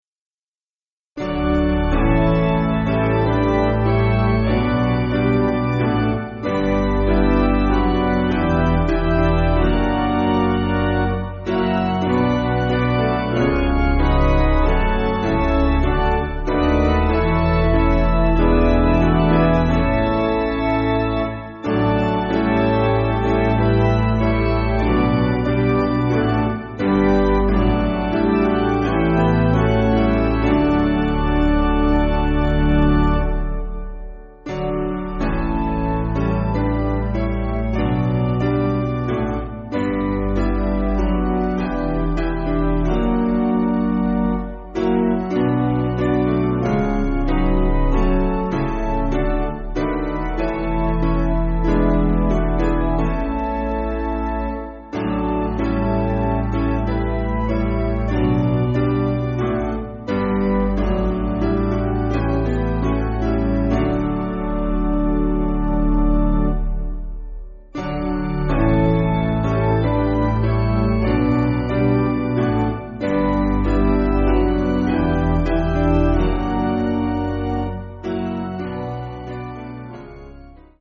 Basic Piano & Organ
(CM)   8/G